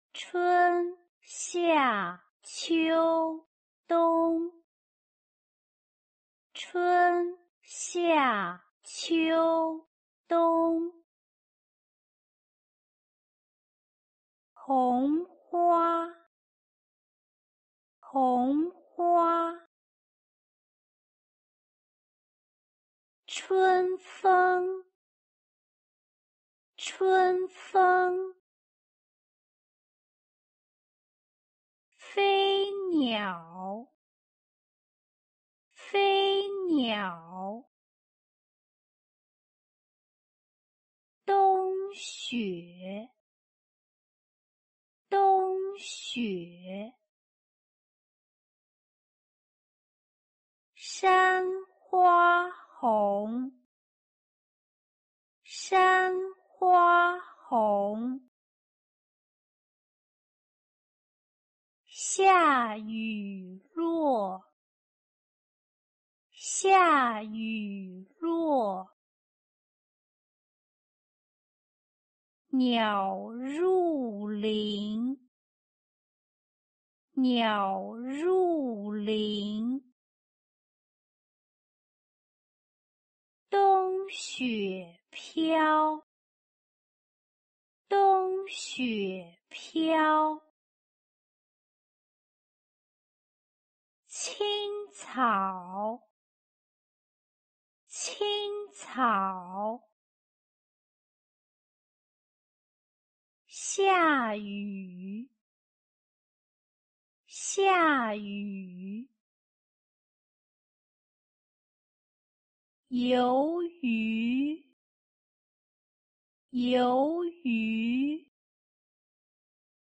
AUDIO DEL DICTADO_TEMA
DICTADOTEMA.mp3